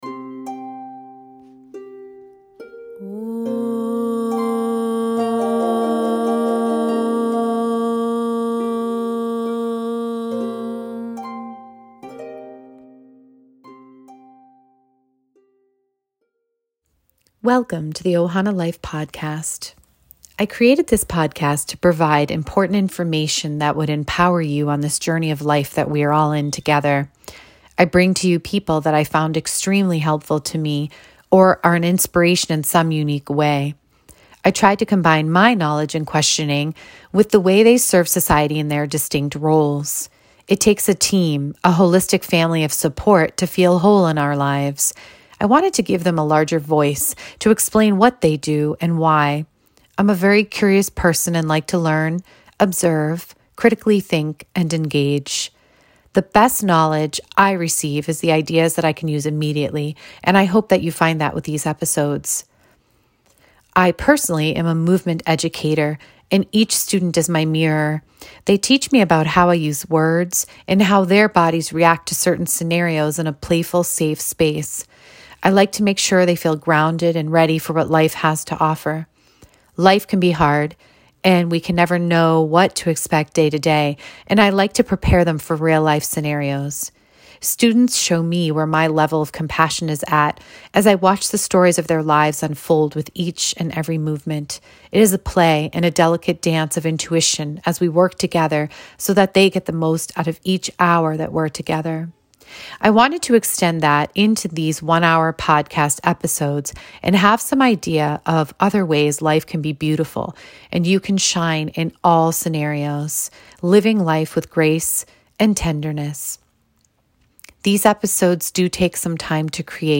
I had figured she would be a perfect candidate to have a nice candid informational session about what I do in classes, in case people were unsure or scared to try.